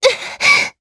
Erze-Vox_Damage_jp_01.wav